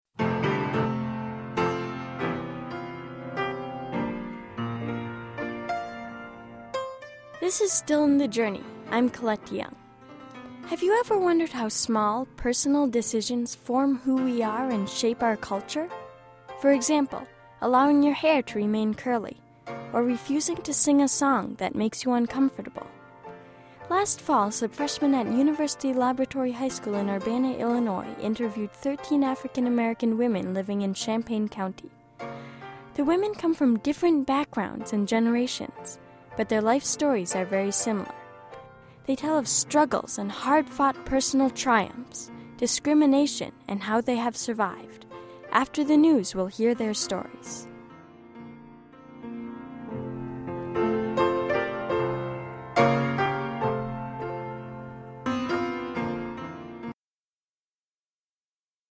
Radio documentary produced by University Laboratory High School Freshman students. Composed of interviews with 13 African-American women in Champaign County.